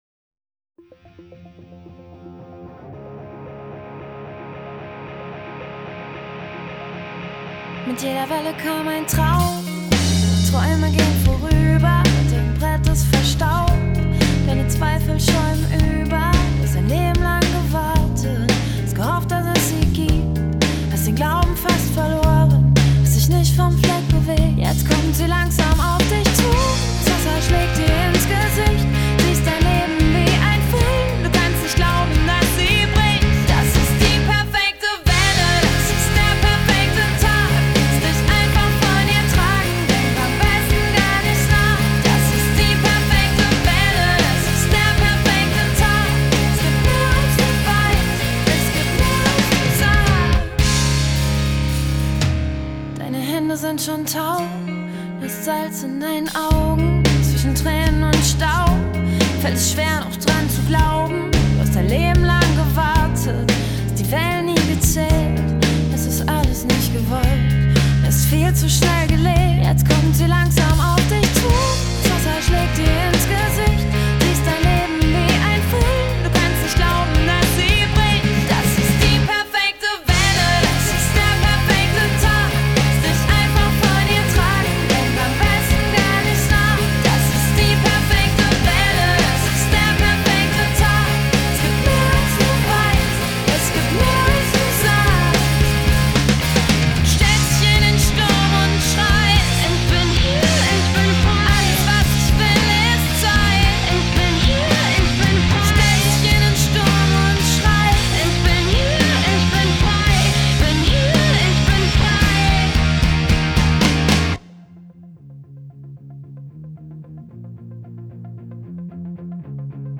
Pop GER